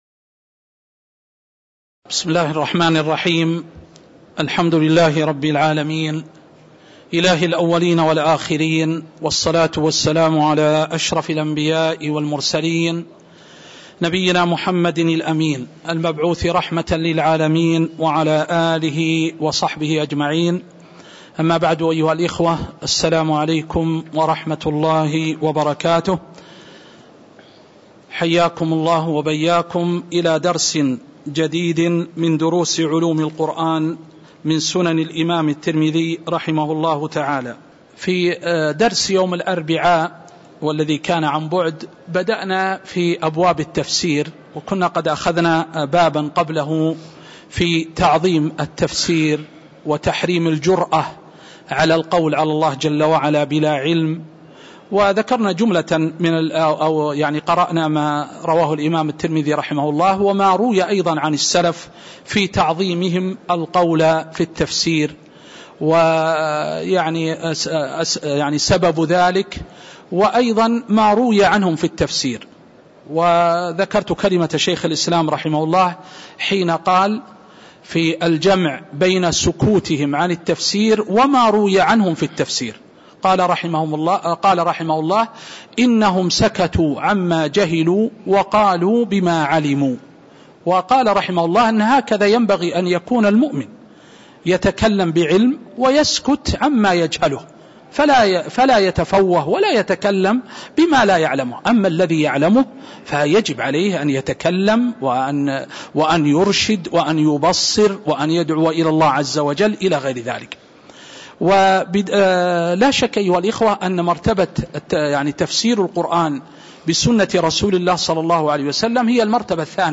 تاريخ النشر ١١ صفر ١٤٤٣ هـ المكان: المسجد النبوي الشيخ